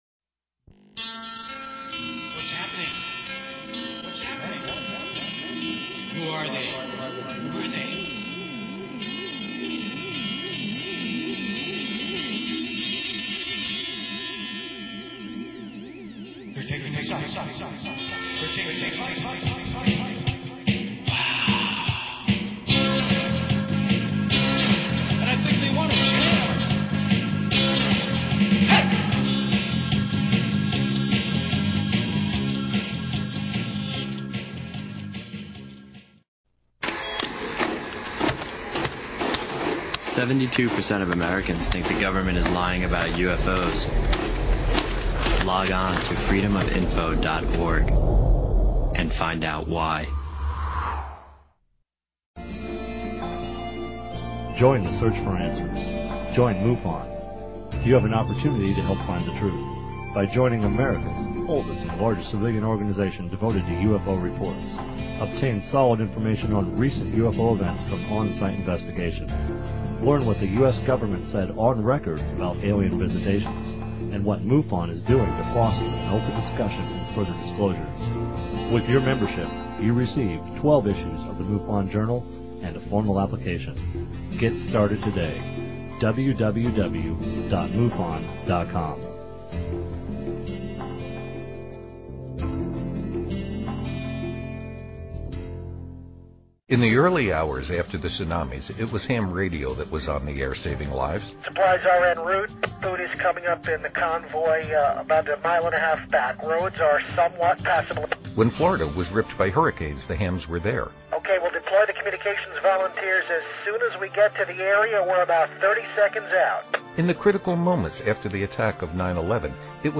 Talk Show Episode, Audio Podcast, Skywatch_Hour and Courtesy of BBS Radio on , show guests , about , categorized as